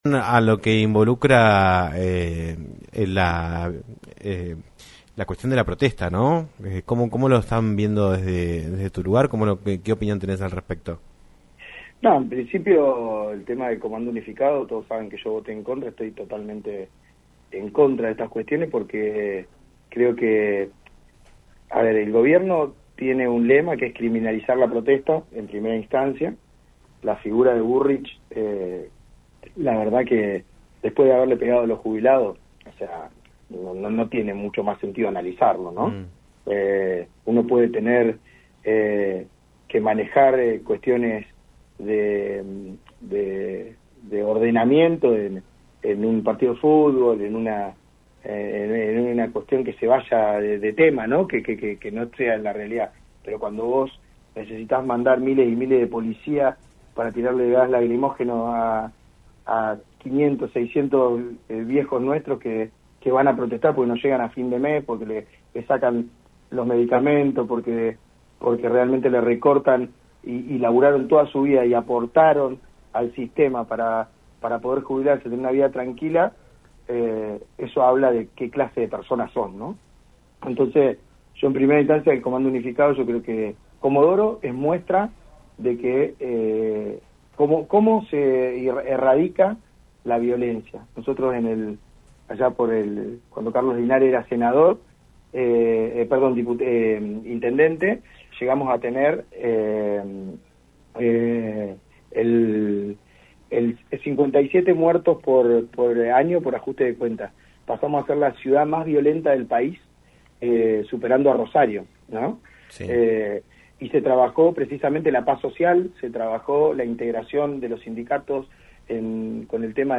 Gustavo Fita, diputado provincial por el bloque Arriba Chubut, habló en “Un Millón de Guanacos” por LaCienPuntoUno sobre la batalla contra la inseguridad que propone el gobierno provincial alineado con Nación.